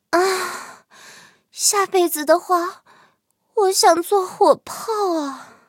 M10狼獾被击毁语音.OGG